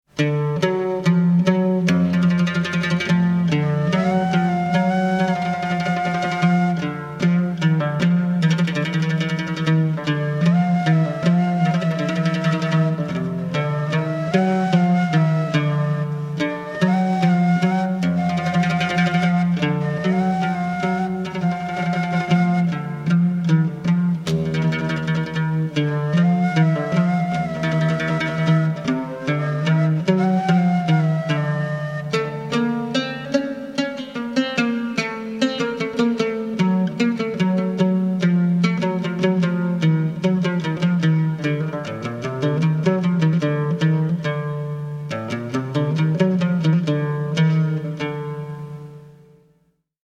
Découvrez une méthode unique pour apprendre le Oud